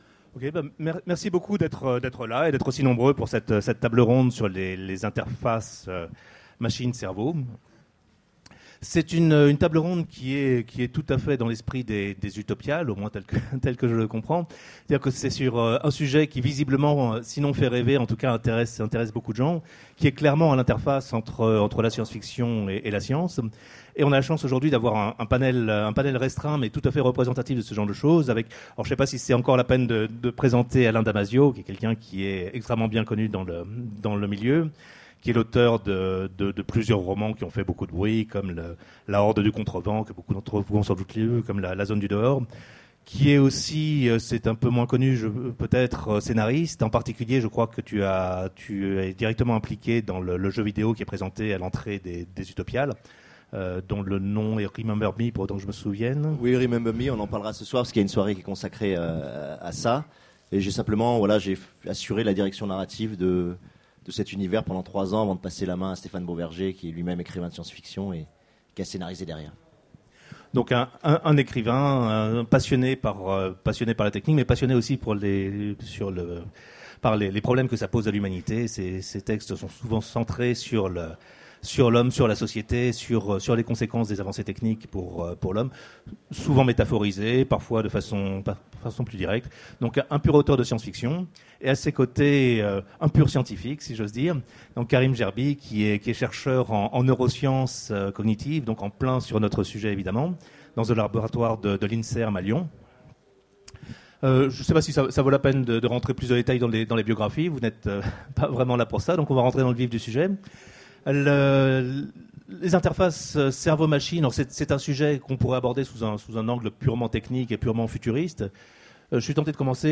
Utopiales 13 : Conférence Interface cerveau/machine